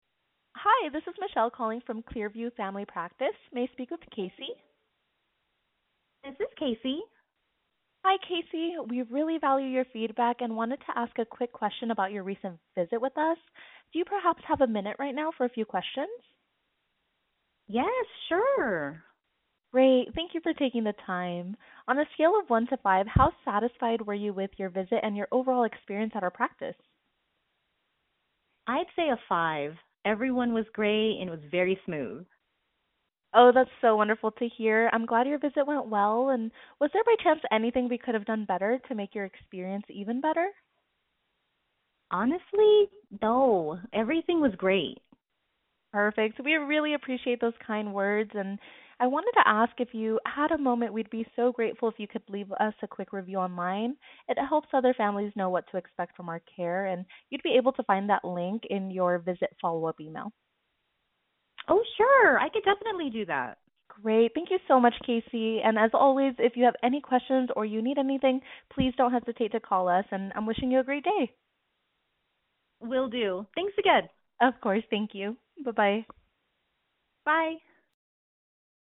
Feedback Survey Call
HUMAN RECEPTIONIST
Feedback-Survey-Call-Human.mp3